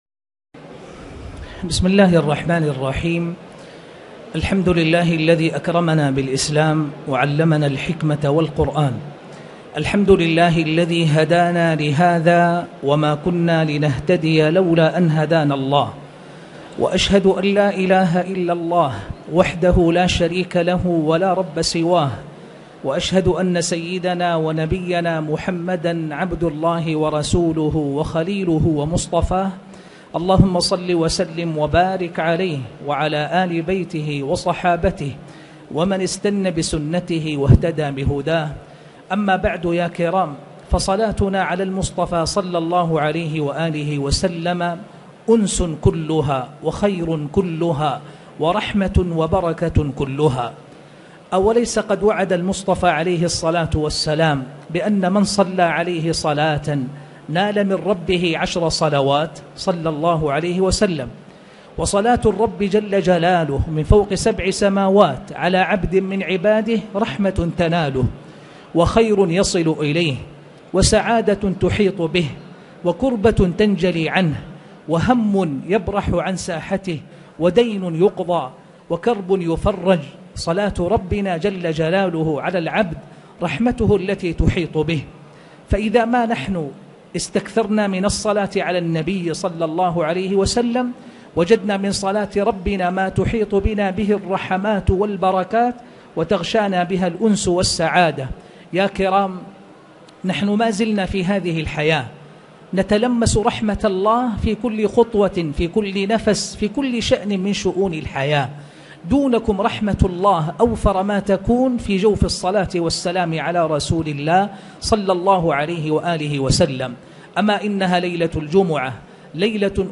تاريخ النشر ١٩ محرم ١٤٣٨ هـ المكان: المسجد الحرام الشيخ